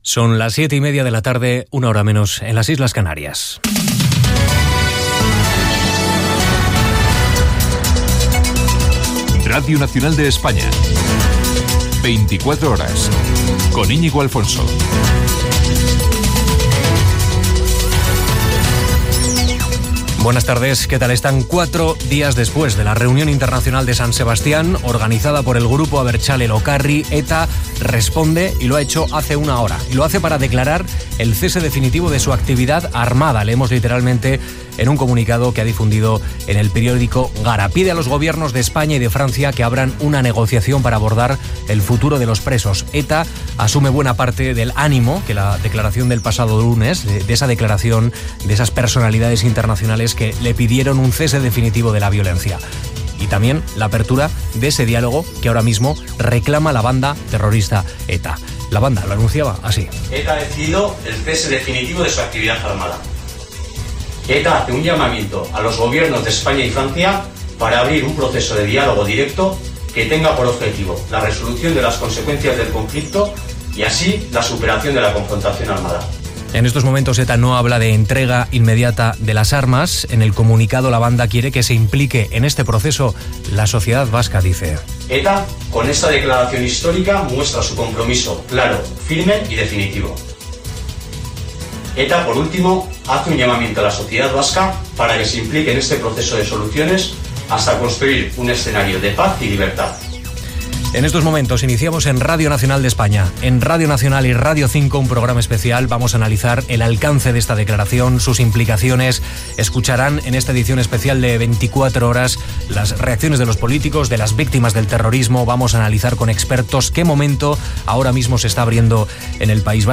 Programa especial dedicat a l'anunci d'ETA que posa fi a la seva activitat armada. Amb declaracions del president del govern espanyol José Luis Rodríguez Zapatero, el líder del Partido Popular Mariano Rajoy, etc.
Informatiu